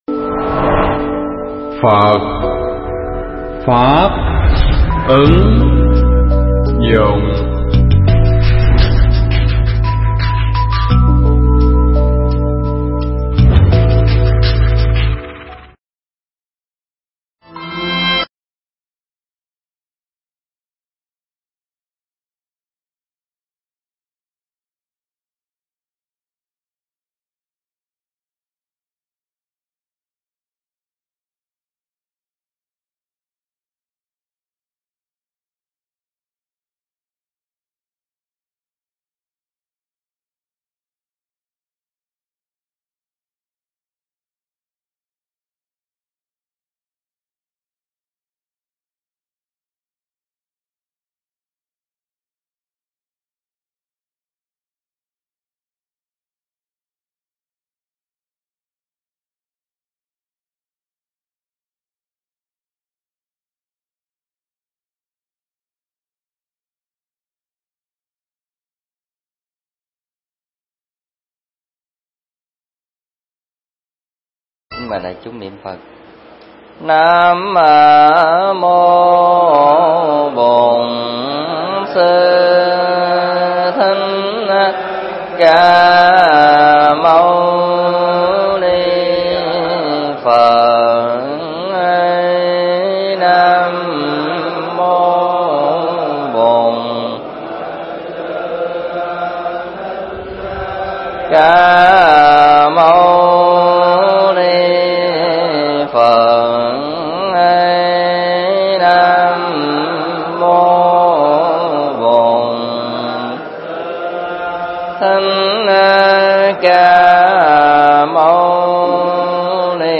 pháp thoại Kinh Kim Cang 8
tại Tu Viện Tường Vân